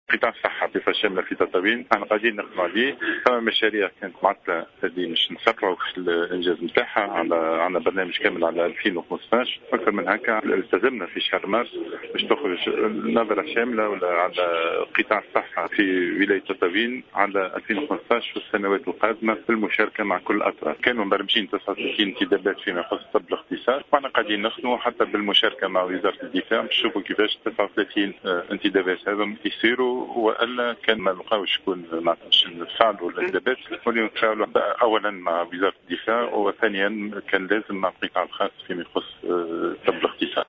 Le ministre de la santé publique Said Aïdi, a indiqué ce samedi 28 février 2015, en marge de sa visite pour le gouvernorat de Tataouine, que le ministère a pris plusieurs mesures pour soutenir la médecine de spécialité dans la région.